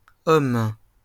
Hommes (French pronunciation: [ɔm]